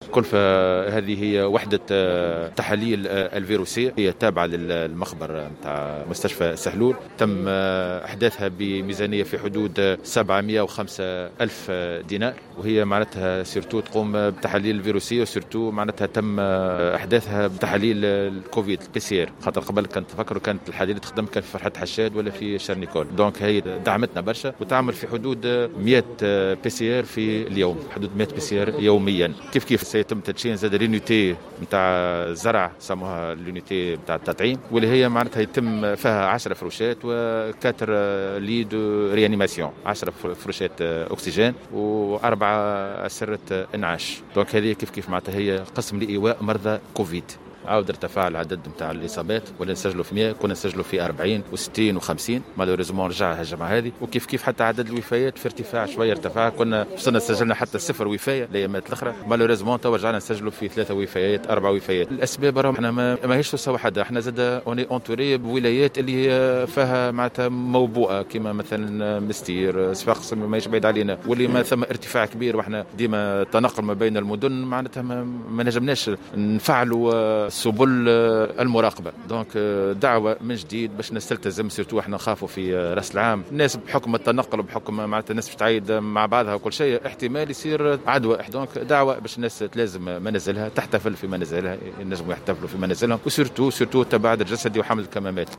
وأكد المدير الجهوي للصحة محمد الغضباني في تصريح للجوهرة "اف ام" أنه تم إحداث هذه الوحدة بميزانية في حدود 705 ألف دينار وهي وحدة خاصة بالتحاليل الفيروسية و تم إحداثها من أجل اجراء تحاليل كوفيد 19 حيث تقوم بانجاز 100 تحليل يوميا.